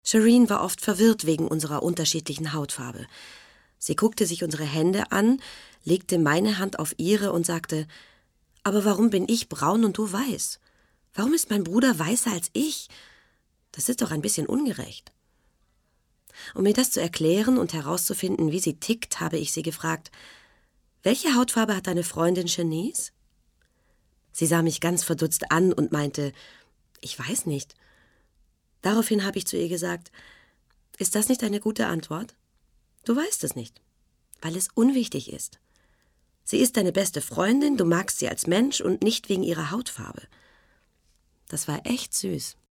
Mittel minus (25-45)